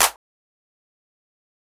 Waka Clap - 2 (4).wav